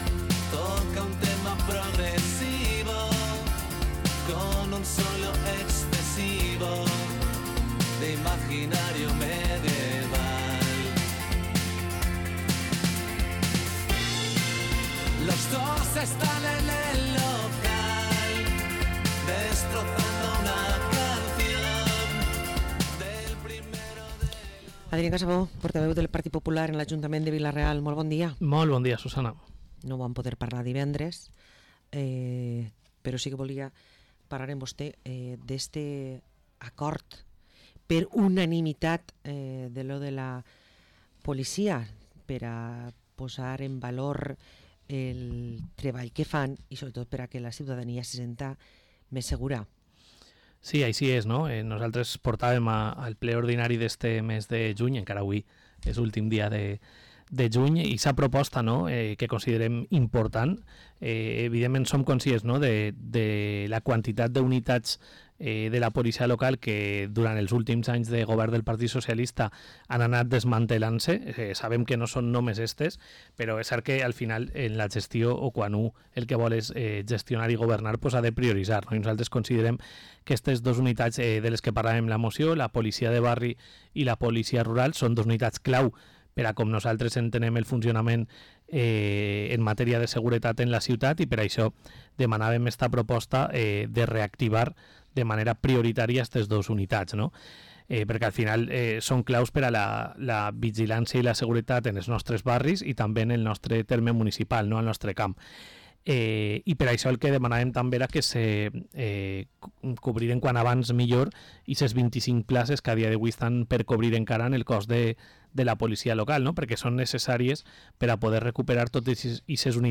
Parlem amb Adrián Casabó, regidor i portaveu del PP a l´Ajuntament de Vila-real